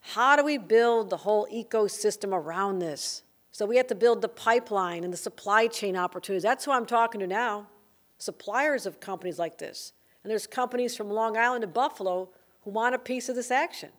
Governor Hochul: